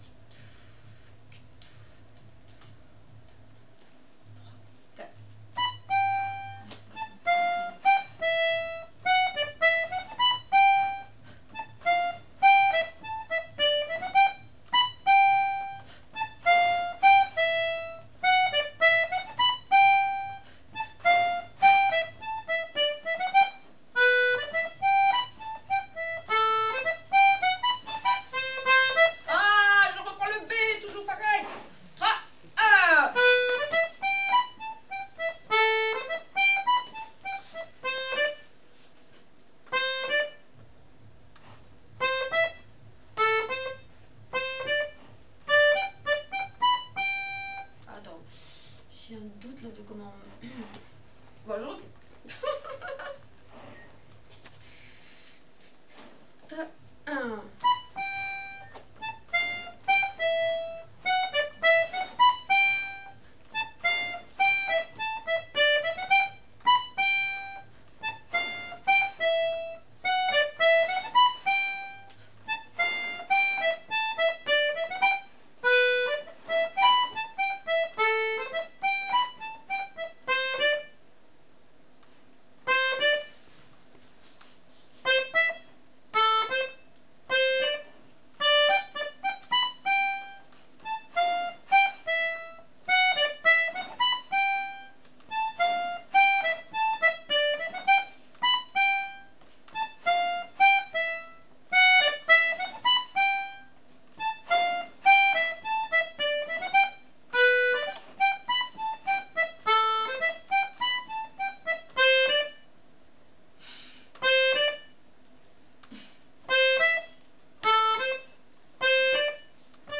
l'atelier d'accordéon diatonique
la mélodie avec les "pêches" très accentuées